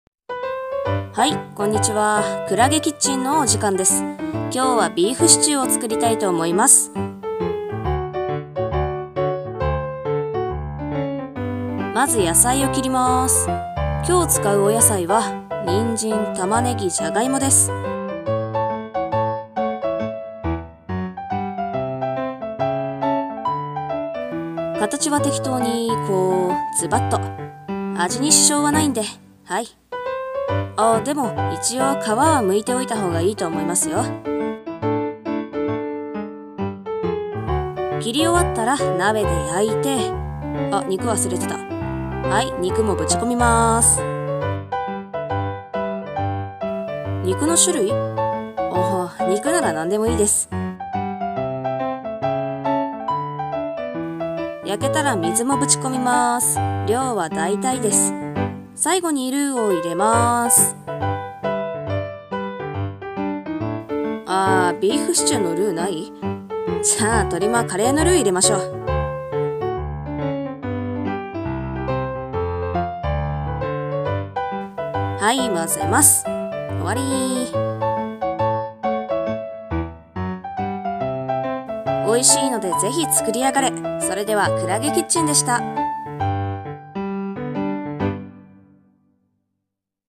【二人声劇】適当クッキング